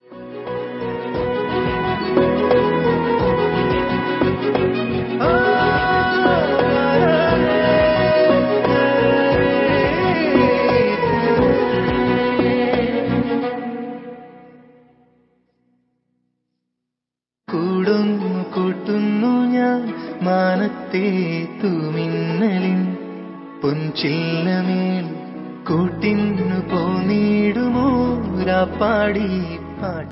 best flute ringtone download | love song ringtone
romantic ringtone download